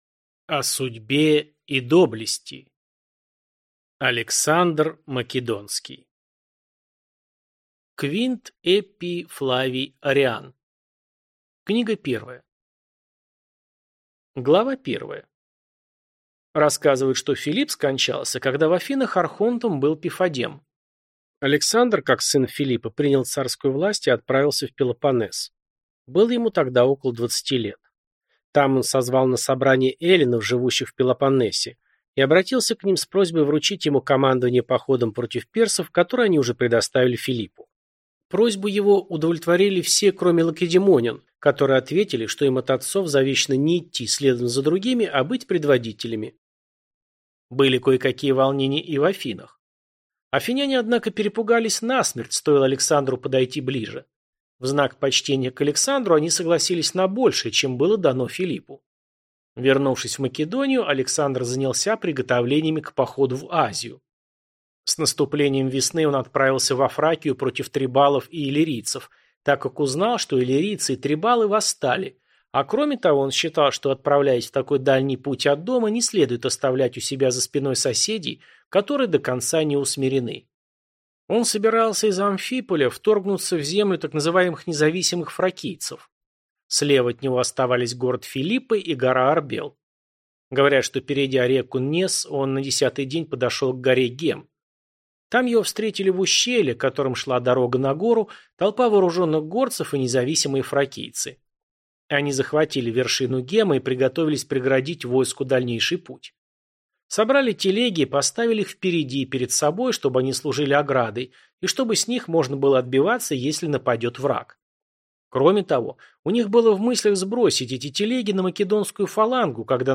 Аудиокнига О судьбе и доблести. Александр Македонский | Библиотека аудиокниг